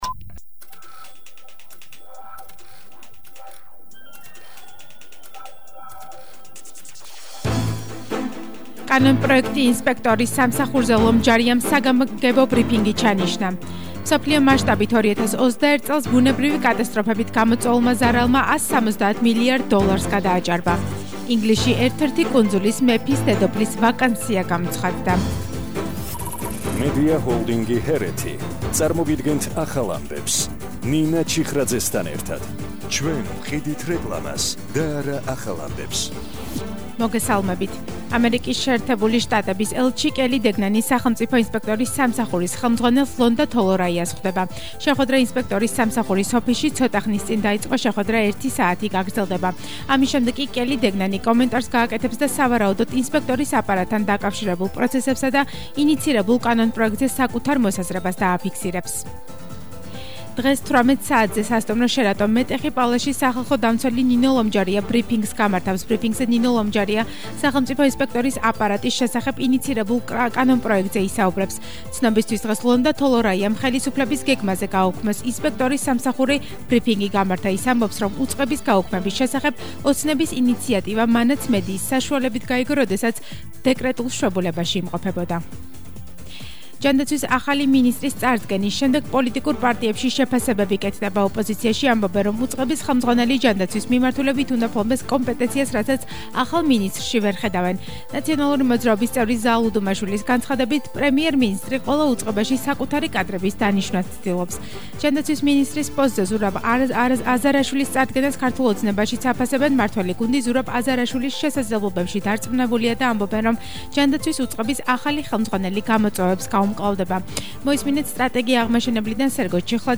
ახალი ამბები 17:00 საათზე – 27/12/21 - HeretiFM